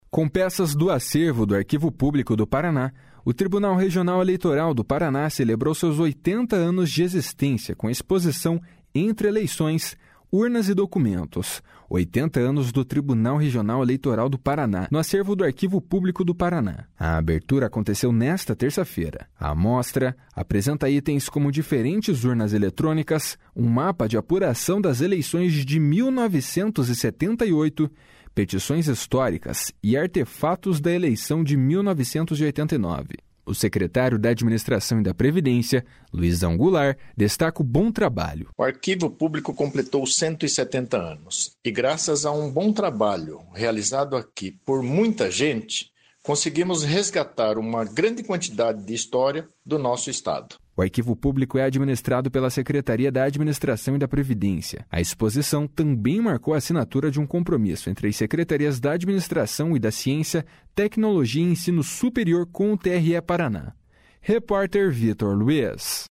A mostra apresenta itens como diferentes urnas eletrônicas, um mapa de apuração das eleições de 1978, petições históricas e artefatos da eleição de 1989. O secretário da Administração e da Previdência, Luizão Goulart, destaca o bom trabalho. // SONORA LUIZÃO GOULART //